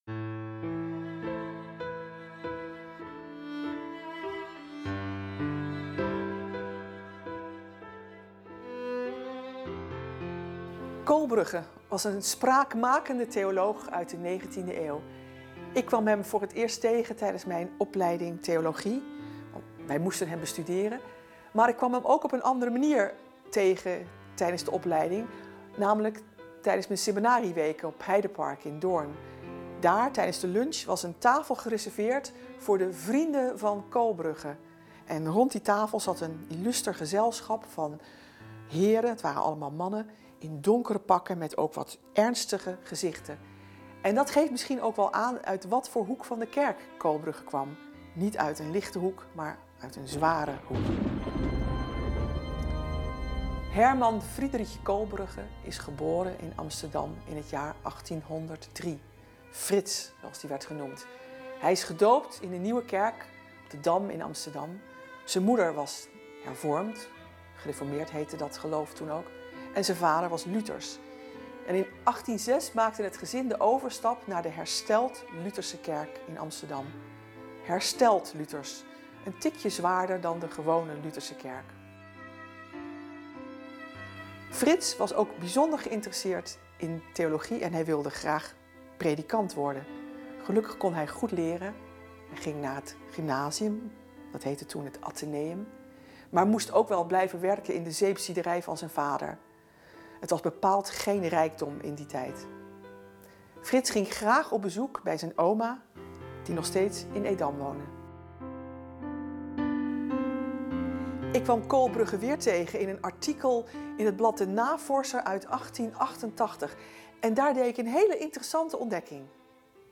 Persoonlijk verhaal over Kohlbrugge…